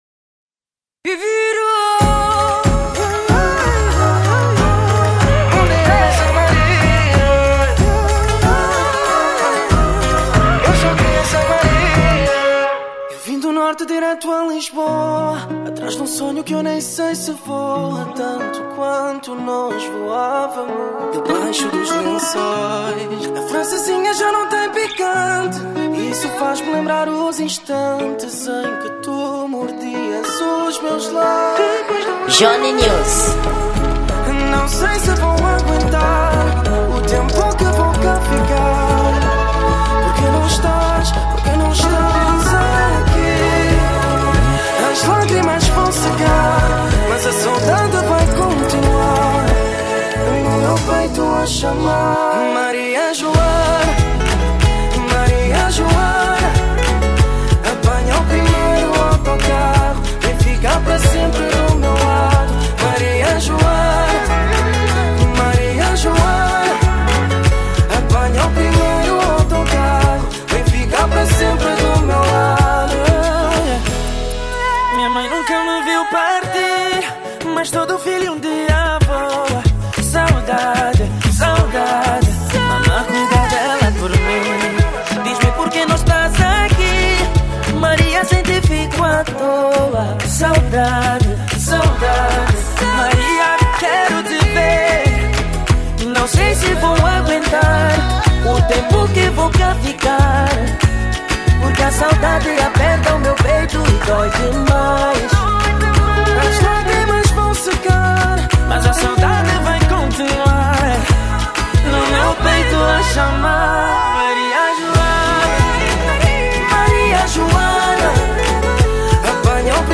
Gênero: Salsa